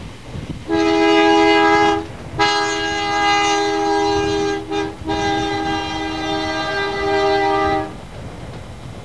The AA-1 was an early Wabco two-chime airhorn used primarily on interurban equipment and some early locomotives and railcars. It was presumably a combination of long and short bell A-1 horns on a single manifold.
Surviving horns on Key System bridge units at the Western Railway Museum at Rio Vista Junction, CA appear to play E and G#.